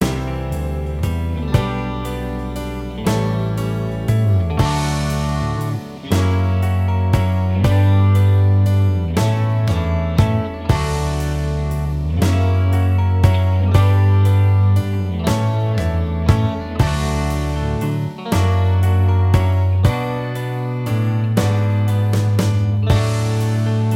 Minus Lead Guitar Rock 4:08 Buy £1.50